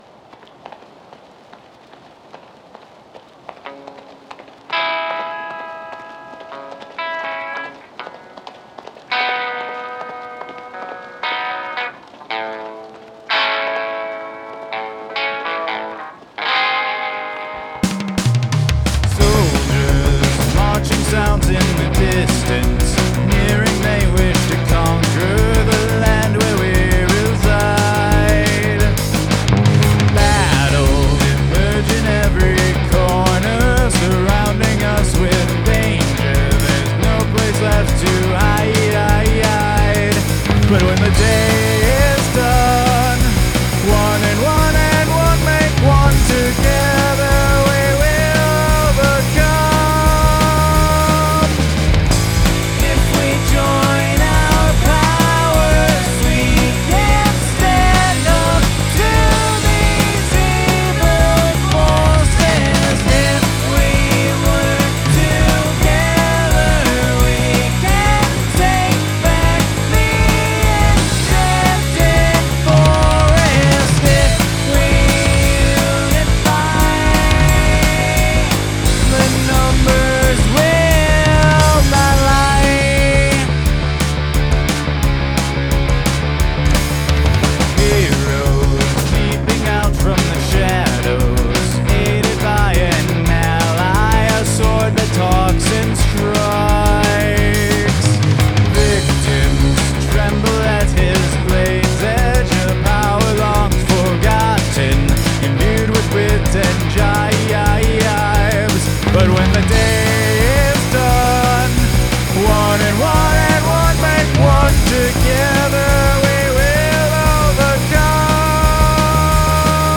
Use the Royal Road chord progression
Royal Road sequence: E Major - A B G#m C#m